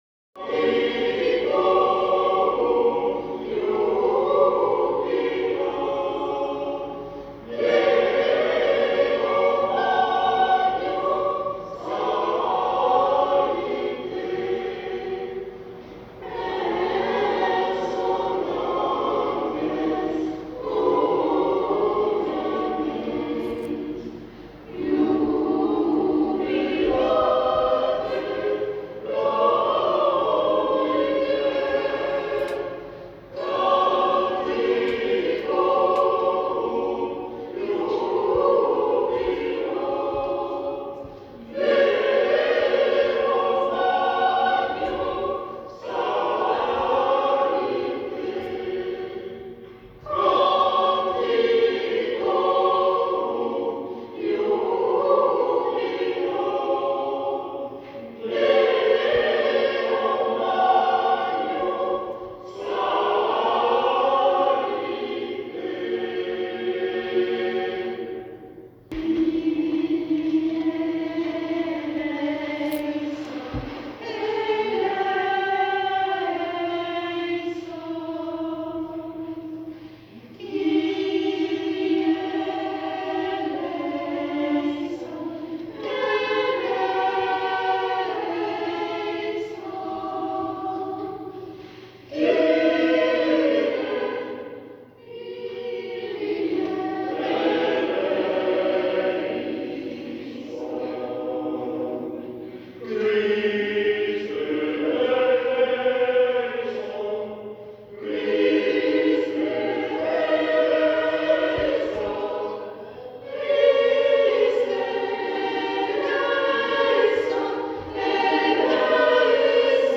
Nos concerts – Les choeurs de la vallée du Paillon
moulinet-messe-st-bernard-1.m4a